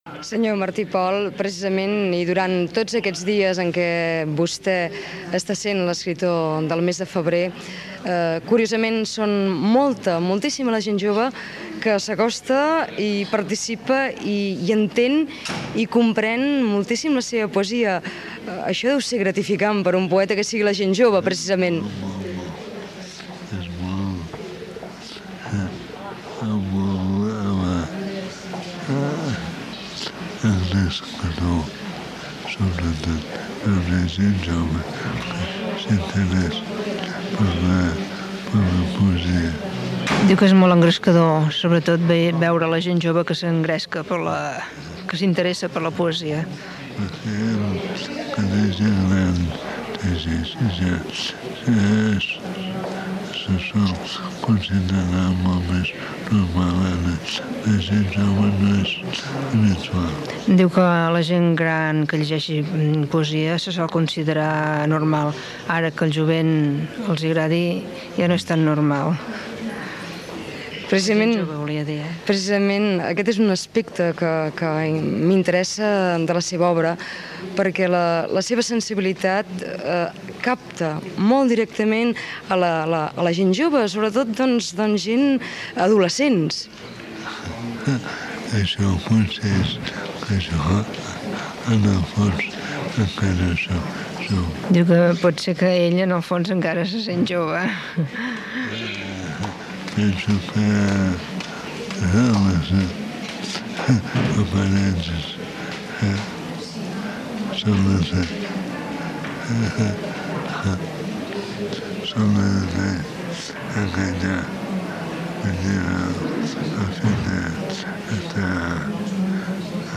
Fragment d'una entrevista a l'escriptor Miquel Martí i Pol